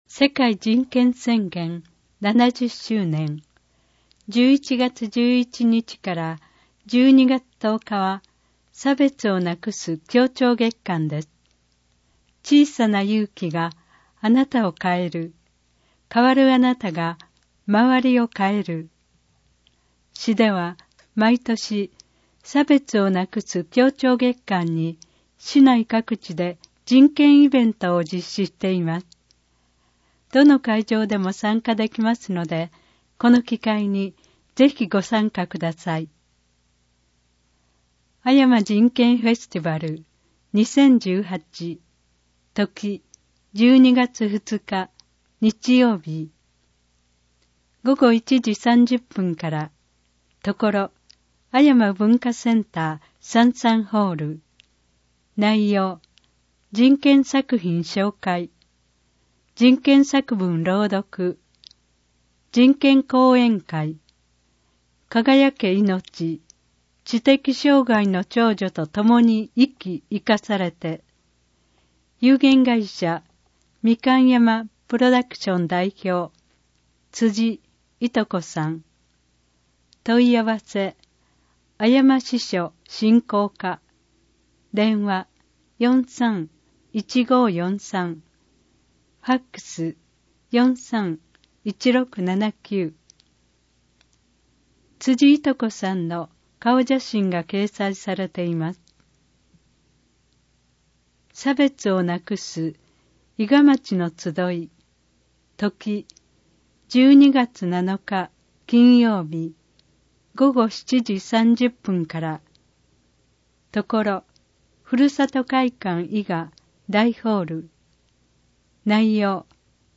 広報いが市のPDF版・音声版をご覧いただけます。